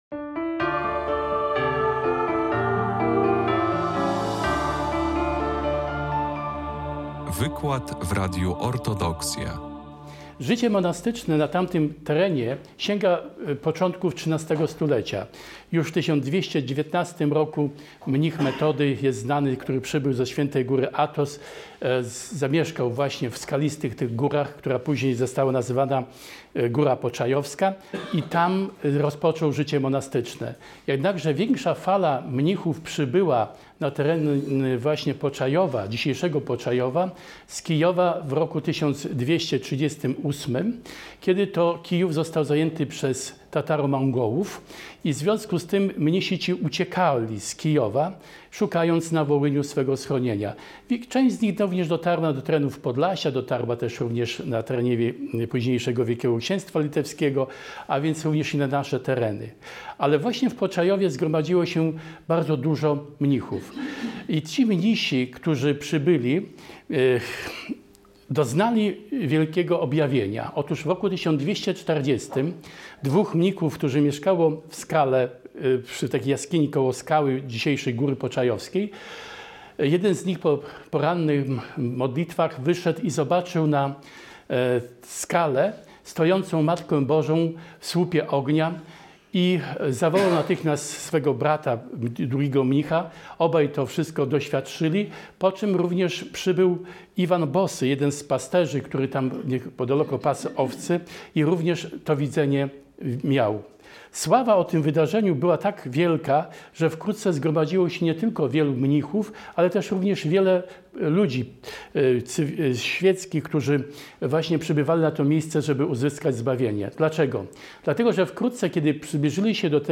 w parafii św. proroka Eliasza w Białymstoku
wygłosił wykład